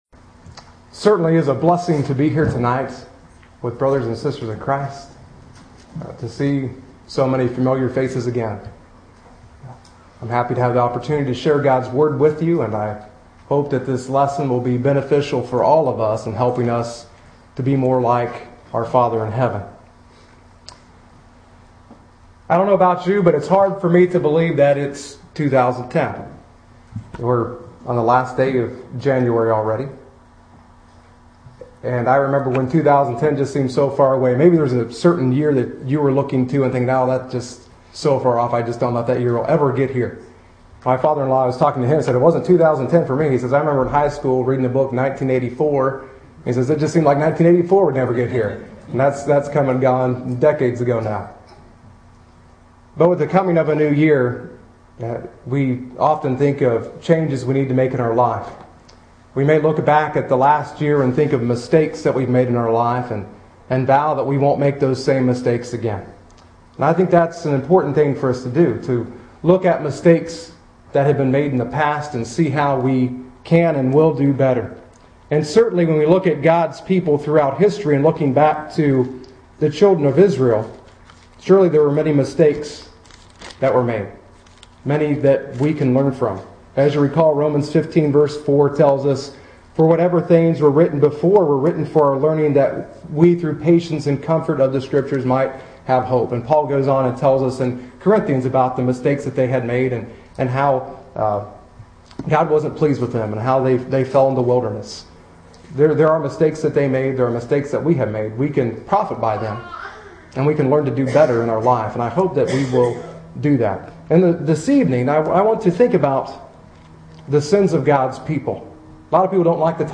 A Lesson from Zephaniah 3:1-2